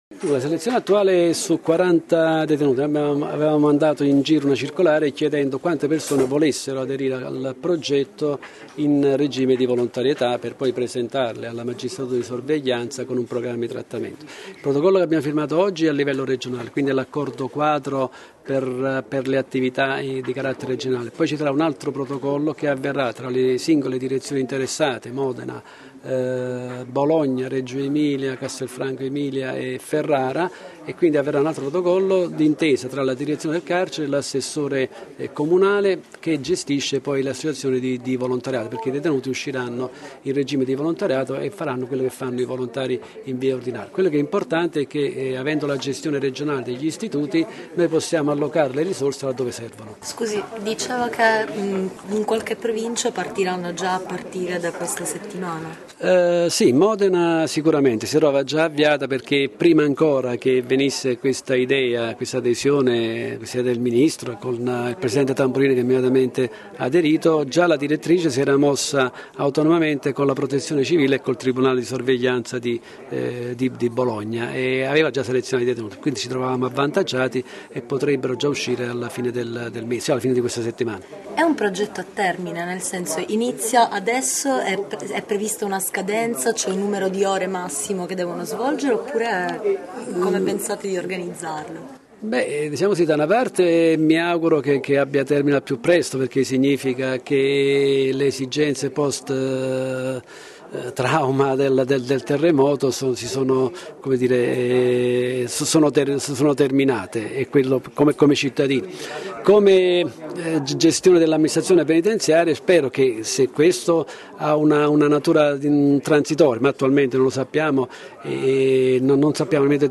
Il vice capo del dipartimento dell’Amministrazione penitenziaria, Luigi Pagano, ha spiegato ai nostri microfoni le modalità di selezione di queste 40 persone che hanno dato la loro disponibilità e sono stati poi selezionati.
Luigi-Pagano-su-detenuti-terremoto.mp3